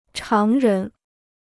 常人 (cháng rén) Dictionnaire chinois gratuit